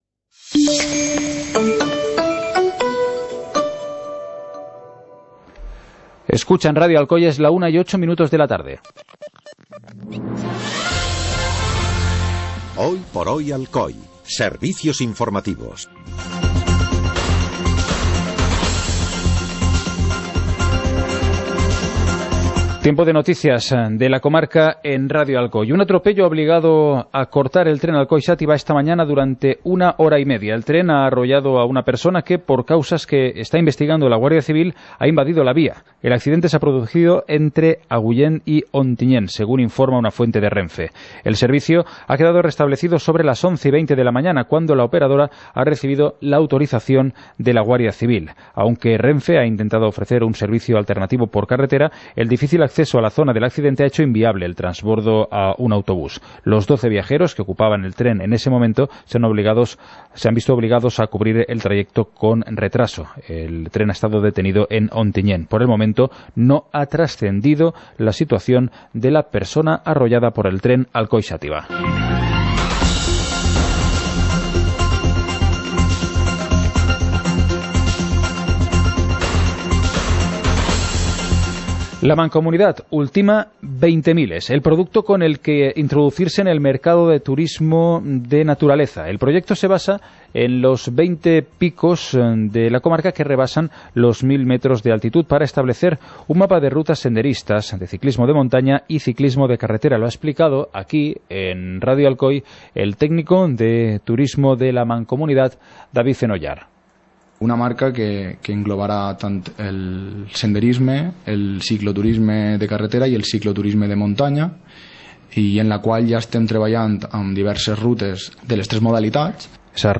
Informativo comarcal - miércoles, 23 de mayo de 2018